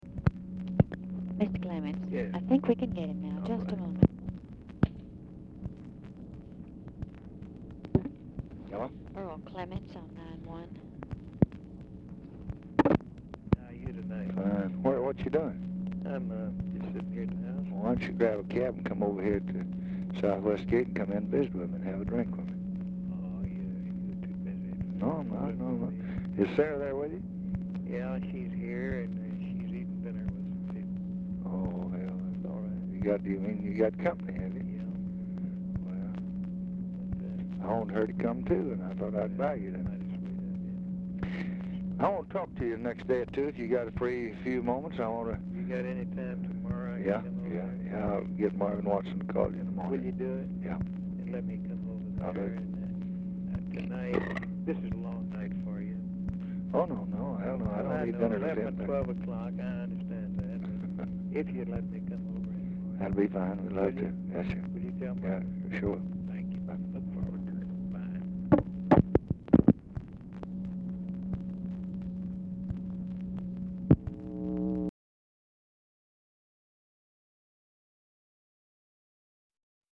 Telephone conversation # 8561, sound recording, LBJ and EARLE CLEMENTS, 8/18/1965, 8:30PM | Discover LBJ
Format Dictation belt
Location Of Speaker 1 Oval Office or unknown location